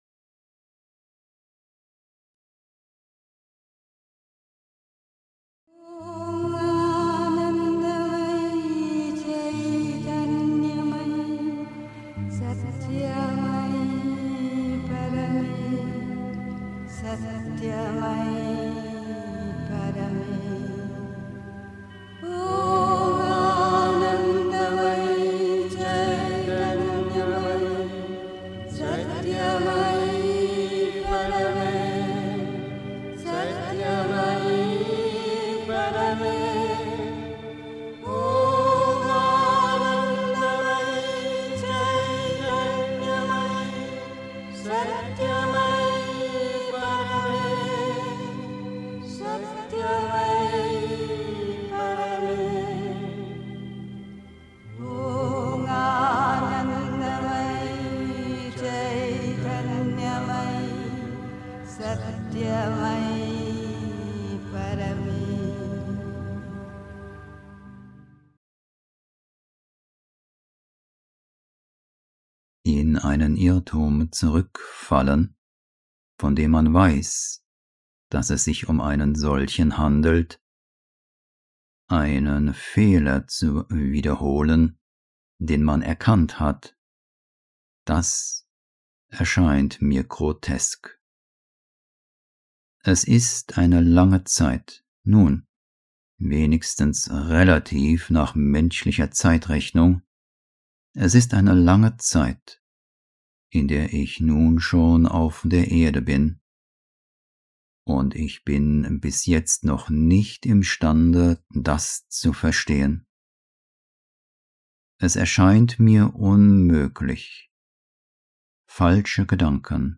1. Einstimmung mit Musik. 2. In den Irrtum zurückfallen (Die Mutter, The Sunlit Path) 3. Zwölf Minuten Stille.